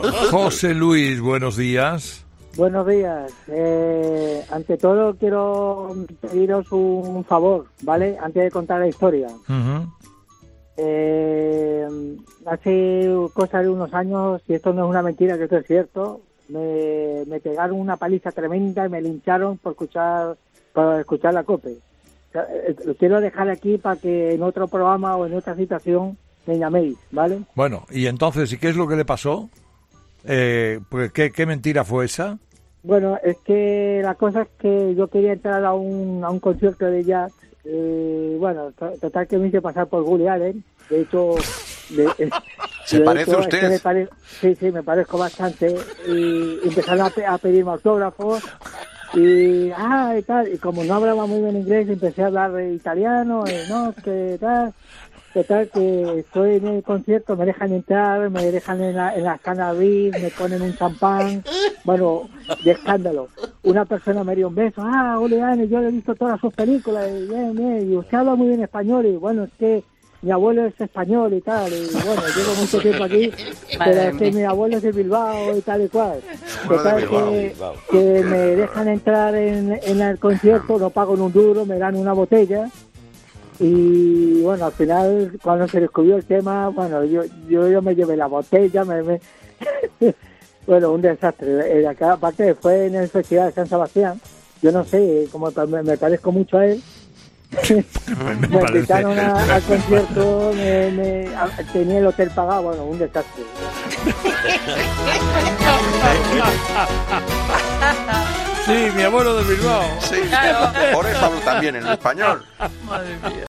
Este jueves los oyentes han confesado los líos más rocambolescos en los que se han metido a costa de decorar la verdad.